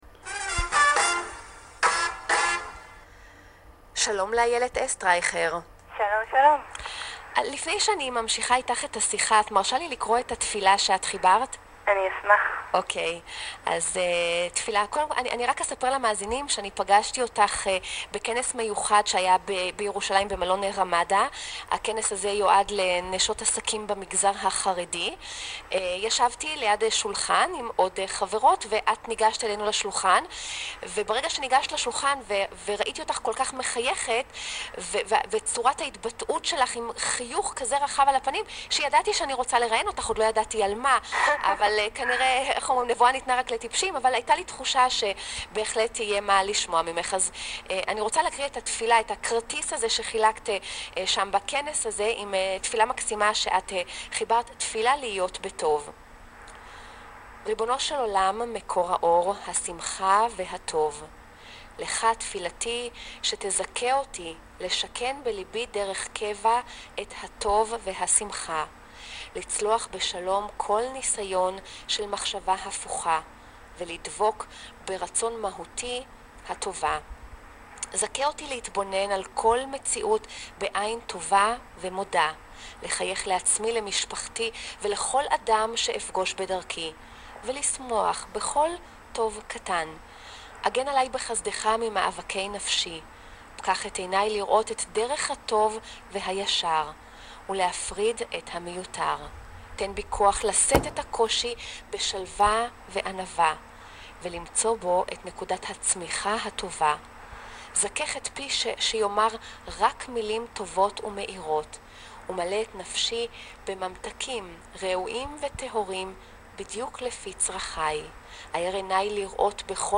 בעקבות התפילה הזו, נולד הראיון הזה: